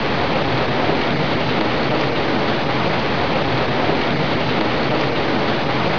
rain2_org.wav